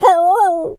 Animal_Impersonations
dog_hurt_whimper_howl_12.wav